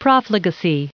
1804_profligacy.ogg